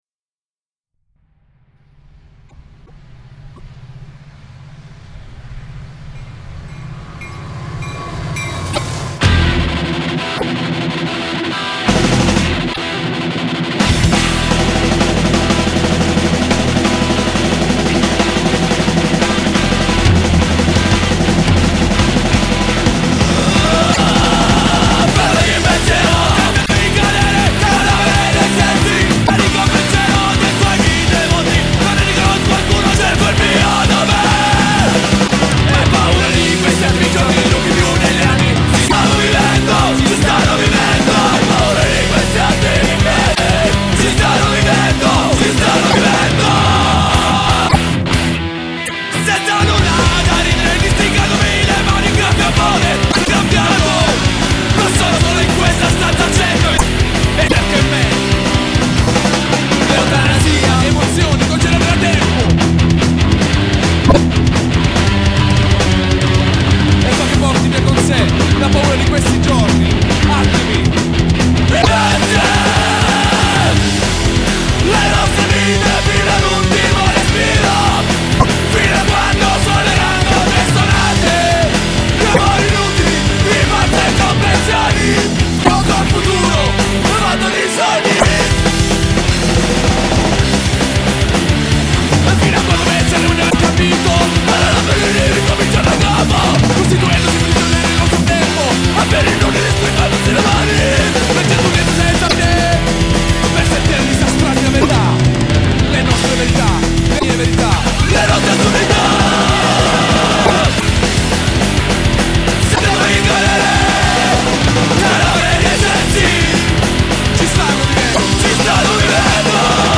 tarantohardcore...............